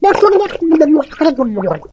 otis_start_vo_05.ogg